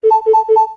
13_Cuckoo.ogg